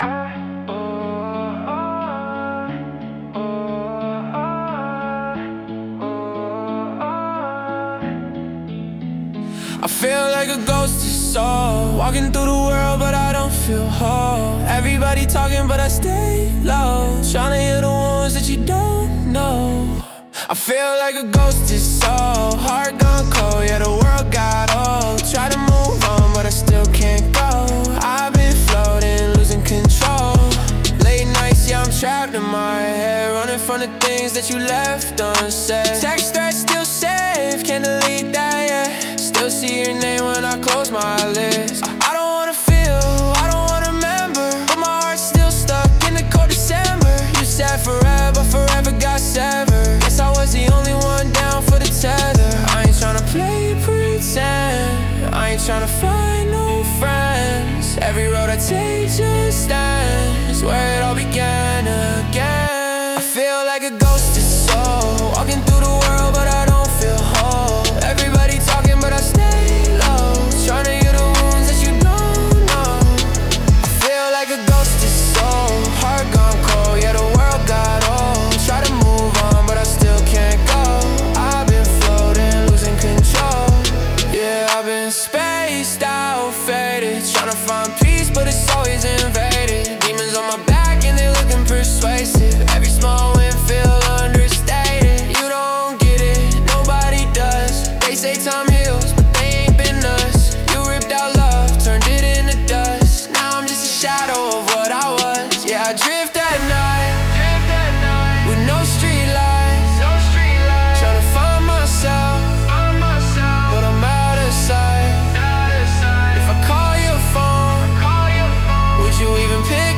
Genre: Emo Rap / Pop Mood: Outcast